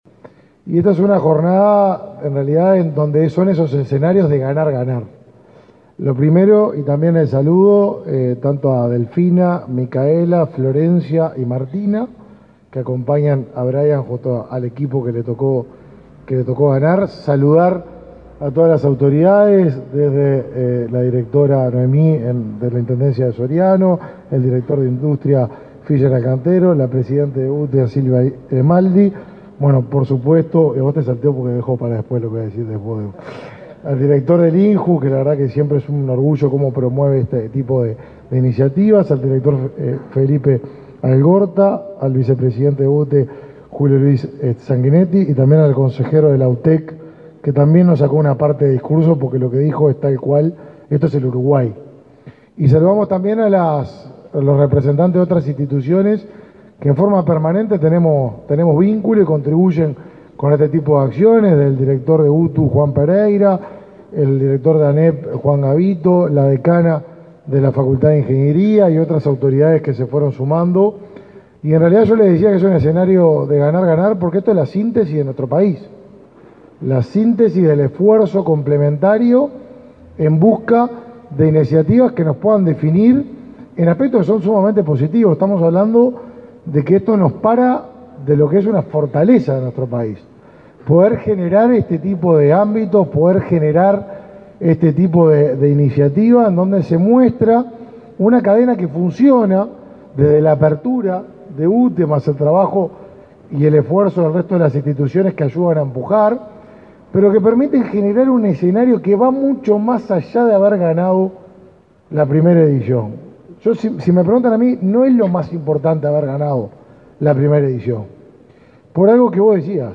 Palabras de autoridades en lanzamiento de Hackatón
Palabras de autoridades en lanzamiento de Hackatón 30/05/2022 Compartir Facebook X Copiar enlace WhatsApp LinkedIn El ministro de Desarrollo Social, Martín Lema; la presidenta de la UTE, Silvia Emaldi; el director nacional de Energía, Fitzgerald Cantero, y el director del INJU, Felipe Paullier, participaron este lunes 30 en el lanzamiento de una hackatón, espacio de experiencias y proyectos con drones, para jóvenes.